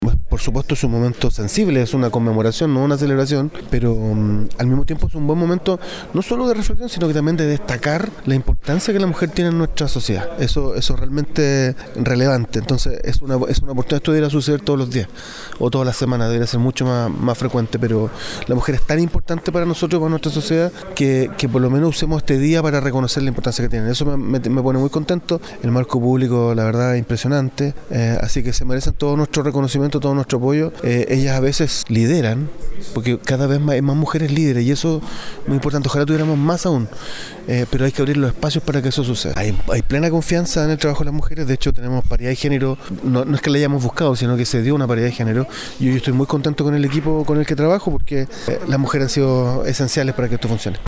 La actividad, realizada en el Centro Cultural, reunió a más de 300 asistentes y contó con la presencia de autoridades locales y regionales, quienes destacaron la importancia de la equidad de género y el rol esencial de la mujer en la comunidad.